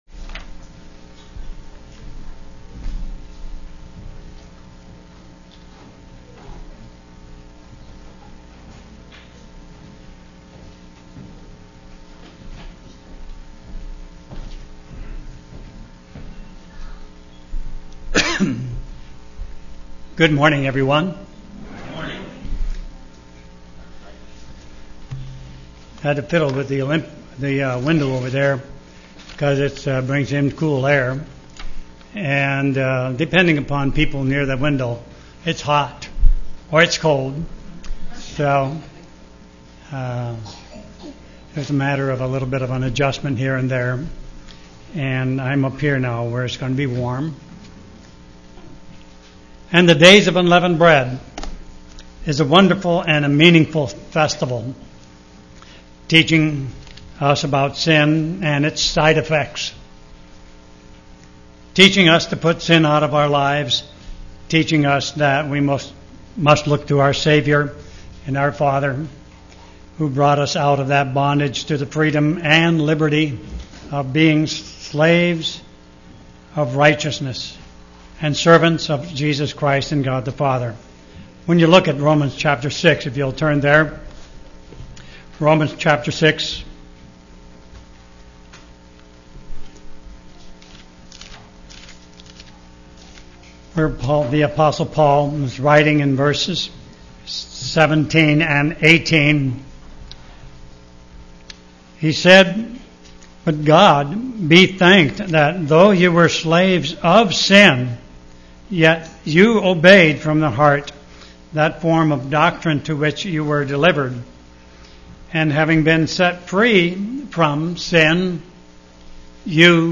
Given in Olympia, WA
UCG Sermon Studying the bible?